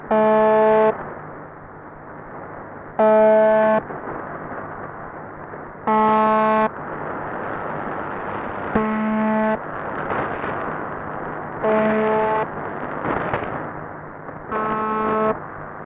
FormatRepeated buzzing sound
A short clip of UVB-76's transmission as recorded in Southern Finland, 860 km (530 mi) away from the station in 2002.
buzz tone, repeating at a rate of approximately 25 tones per minute, 24 hours per day.[1] Sometimes, the buzzer signal is interrupted and a voice transmission in Russian takes place.[3][4][5][6]